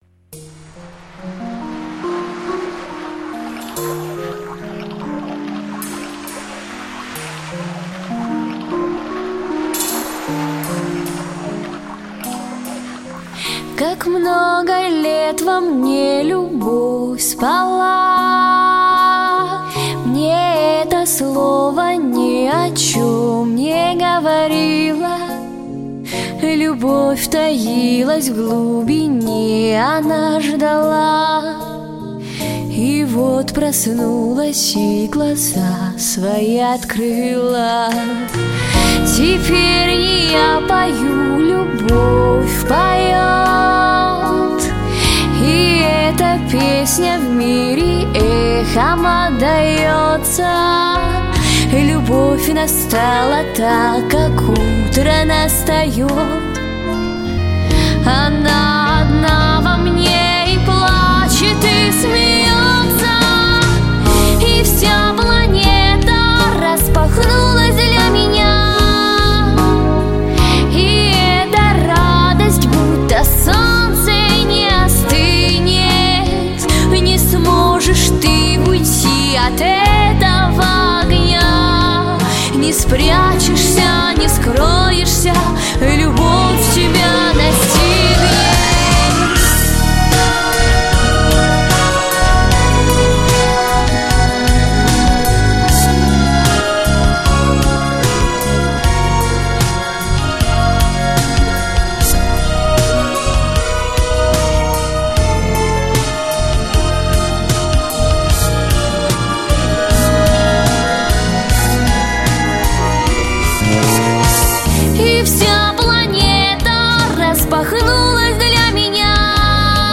• Категория: Детские песни
подростковые песни